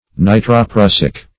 Nitroprussic \Ni`tro*prus"sic\ (? or ?), a. [Nitro- + prussic.]